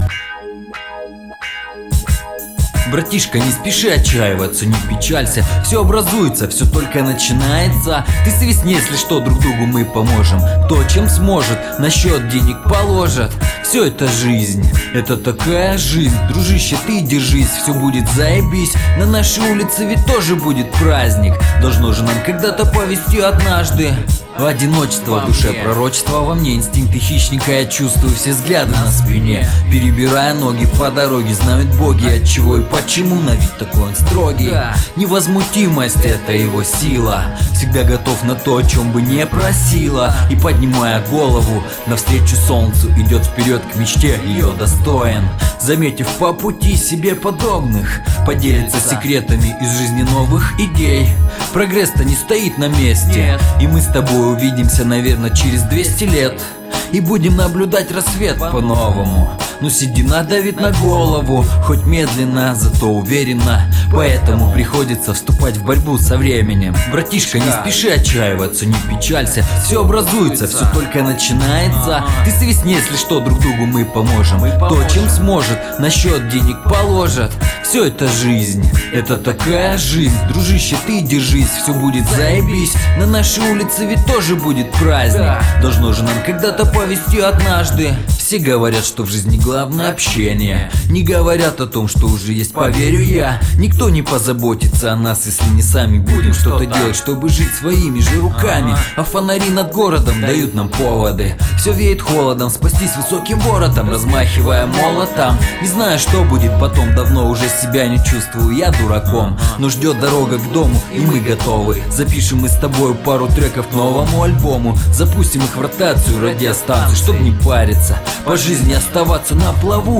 • Жанр: Рэп
Осторожно! не нормативная лексика !